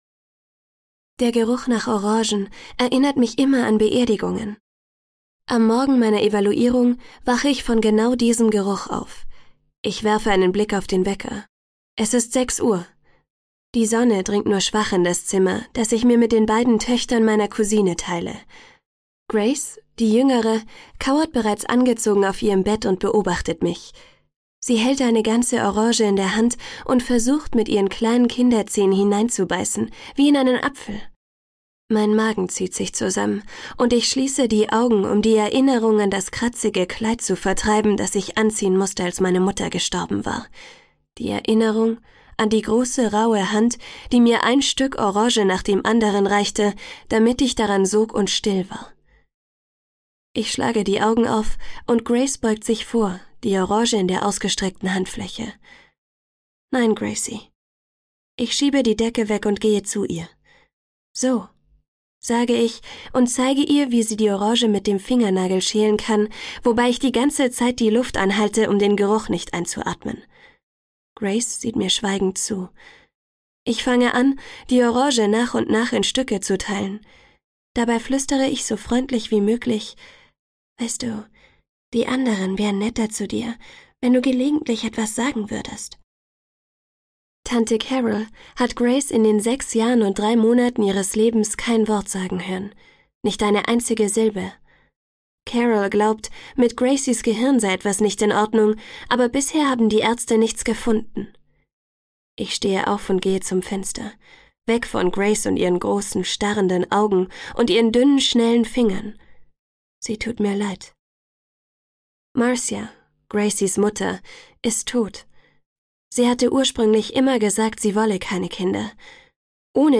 Amor-Trilogie 1: Delirium - Lauren Oliver - Hörbuch